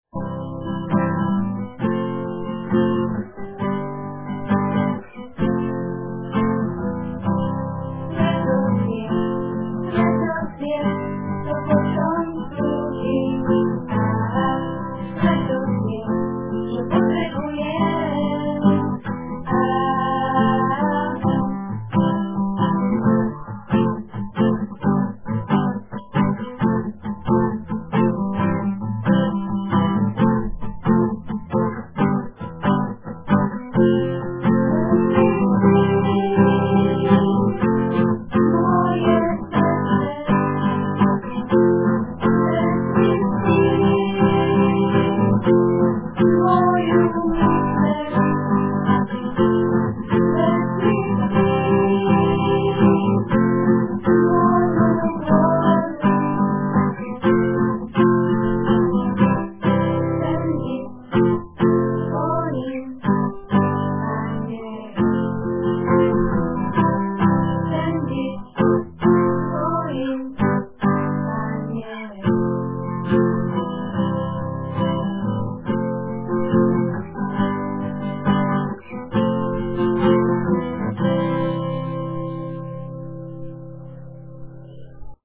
rozdelovac obsahu Hymna: